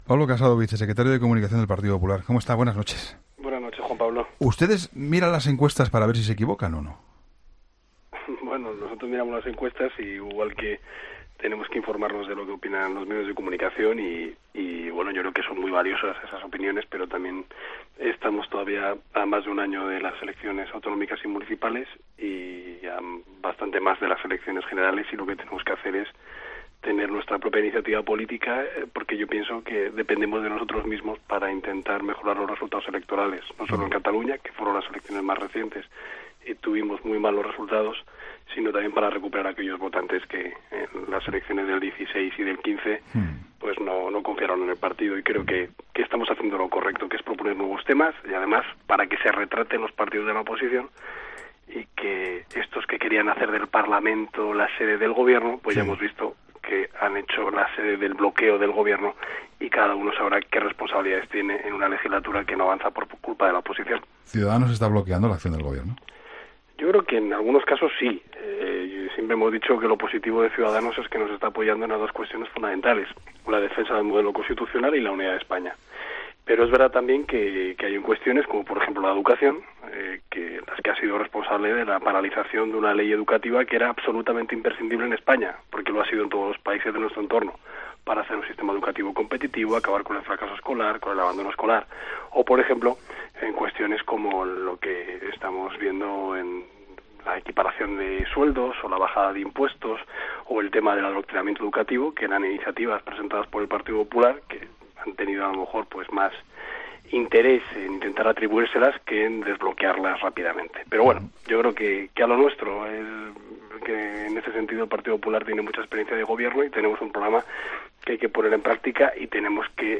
Pablo Casado, vicesecretario de Comunicación del Partido Popular ha analizado en 'La Linterna' el bloqueo institucional que se percibe en el...